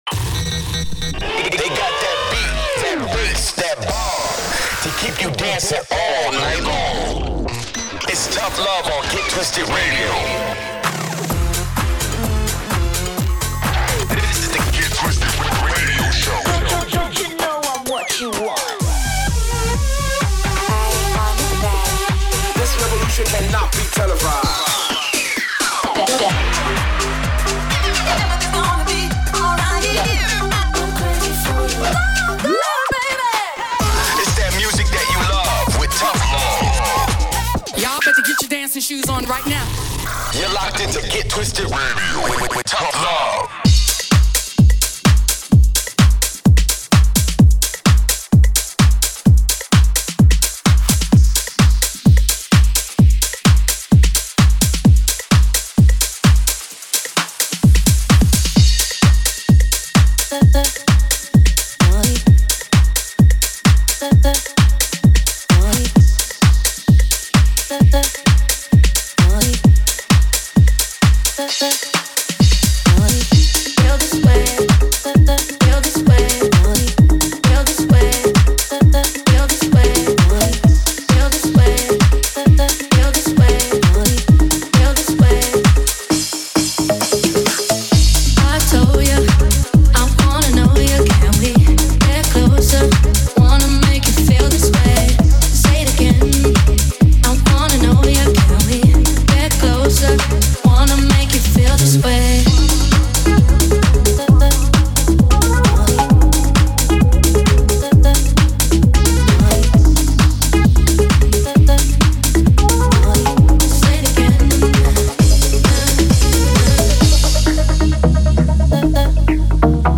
upfront & underground house music